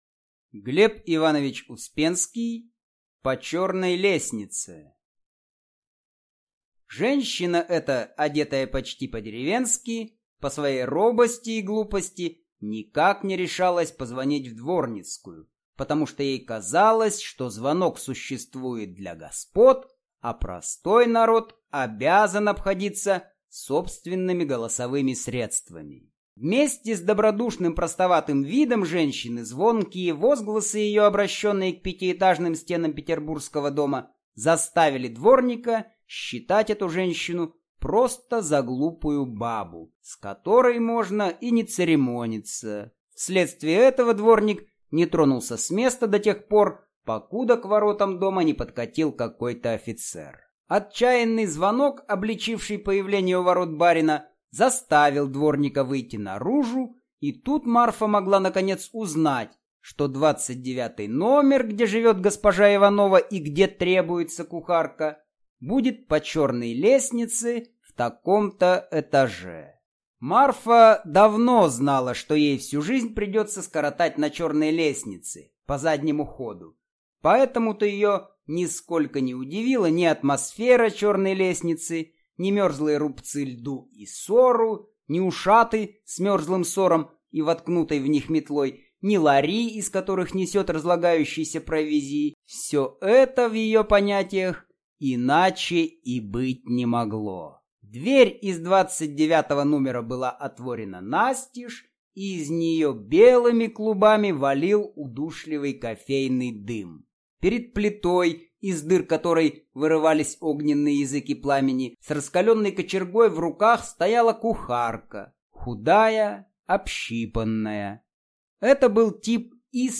Аудиокнига По черной лестнице | Библиотека аудиокниг